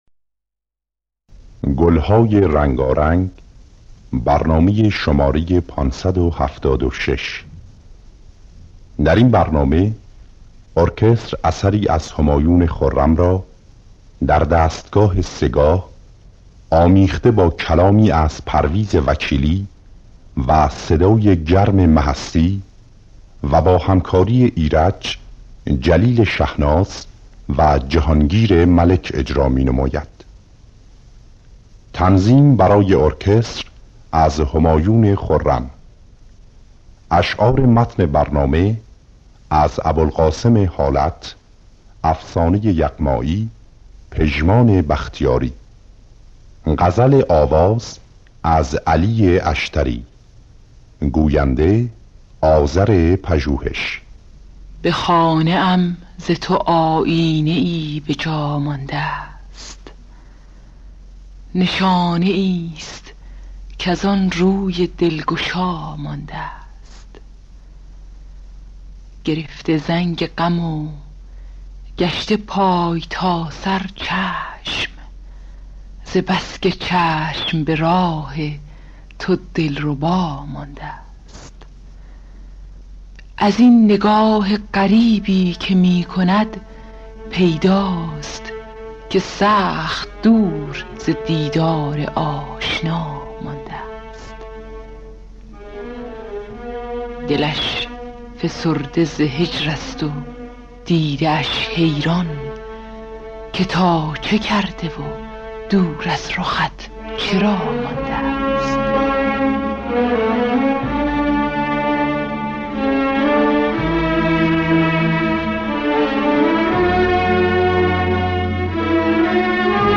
دانلود گلهای رنگارنگ ۵۷۶ با صدای مهستی، ایرج در دستگاه سه‌گاه.